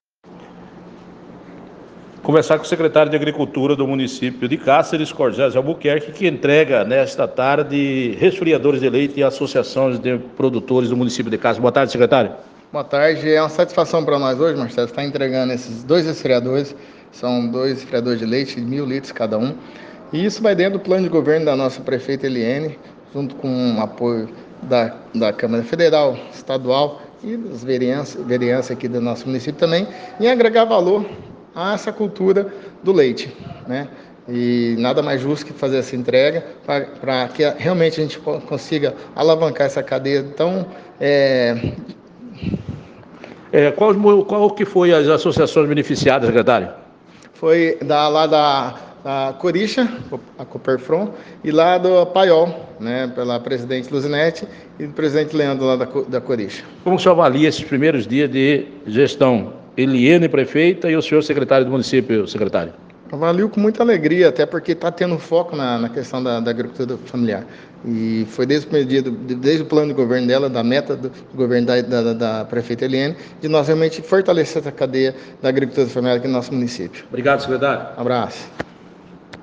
Secretário de agricultura Corgésio e Prefeita Eliene entregam resfriadores de leite, ouça a entrevista com ele abaixo: